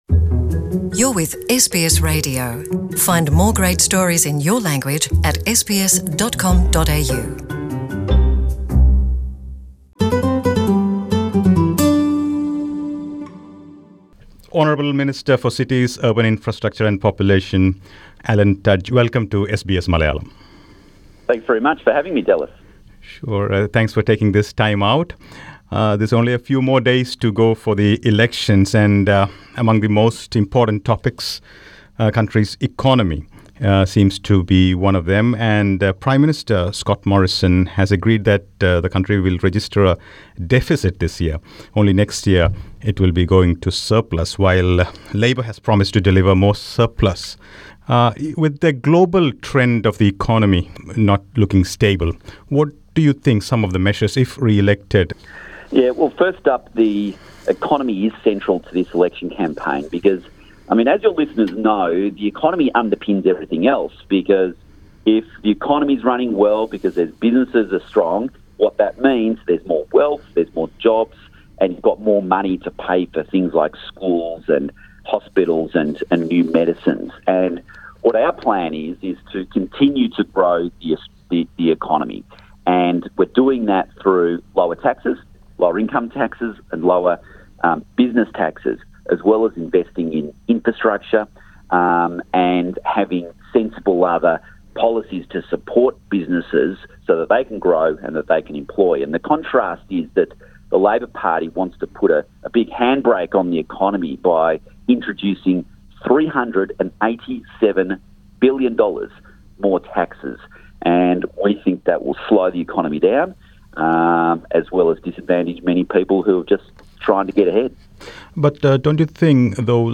With only days remaining for the Federal Elections major parties are out on the streets speaking to people about their important policies. Federal Minister for Cities, Urban Infrastructure and Population, Alan Tudge MP speaks to SBS Malayalam about the important Liberal Party policies.